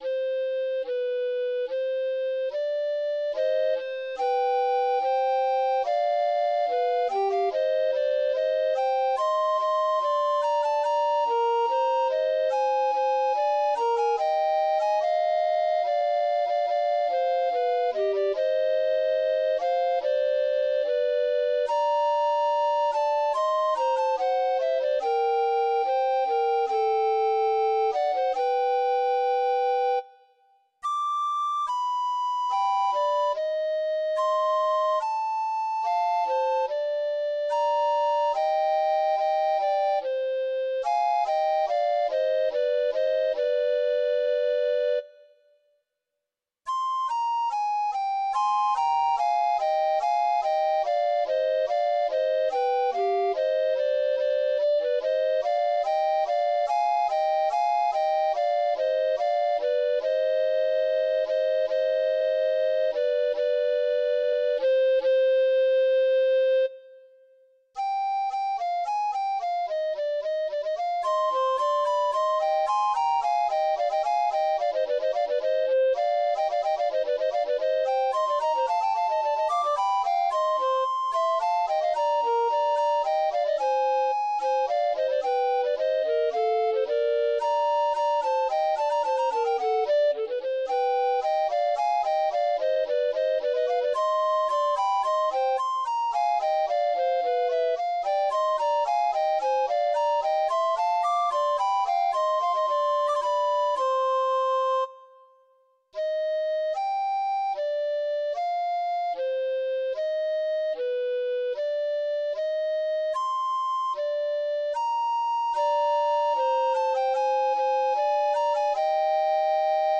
for recorder duet.
Epoque : Baroque Level